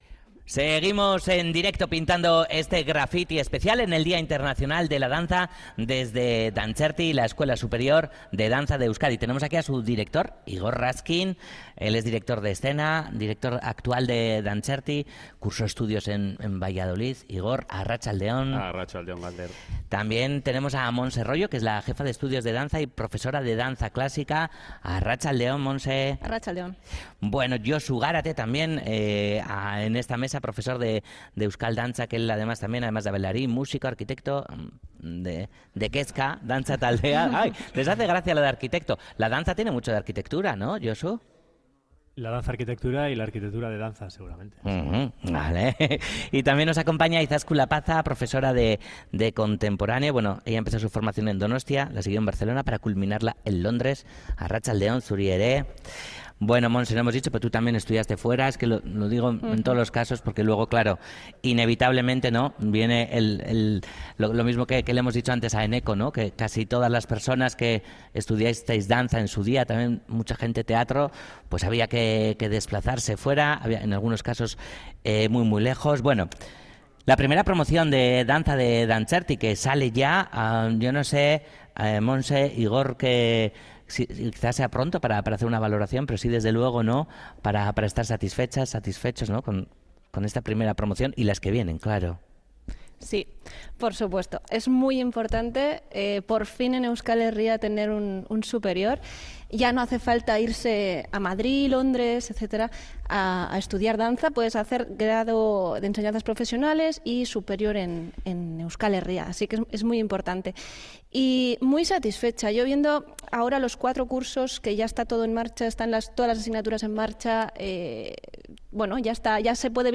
Audio: Graffiti reúne en una mesa redonda a parte del profesorado del centro. Con ellos abordamos el presente y el futuro de la danza y los retos que se plantean.